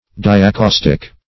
diacaustic - definition of diacaustic - synonyms, pronunciation, spelling from Free Dictionary
Diacaustic \Di`a*caus"tic\, a. [Pref. dia- + caustic.]